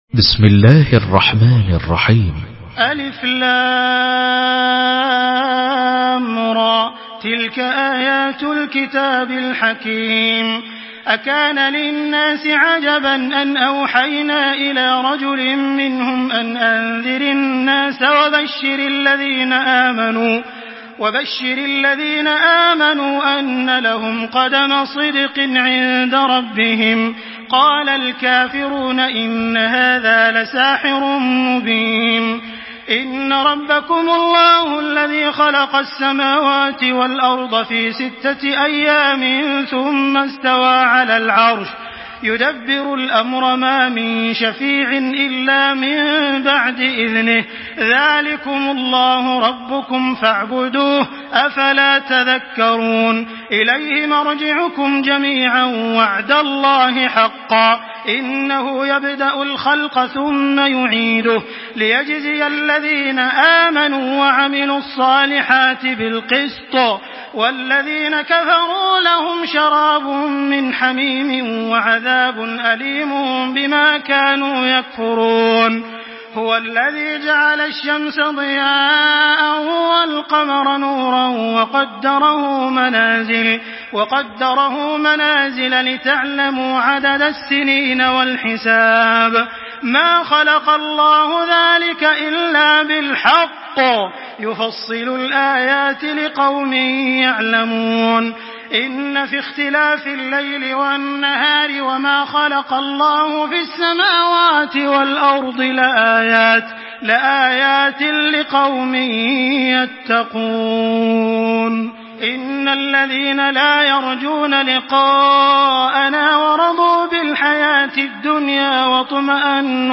Surah ইউনুস MP3 by Makkah Taraweeh 1425 in Hafs An Asim narration.
Murattal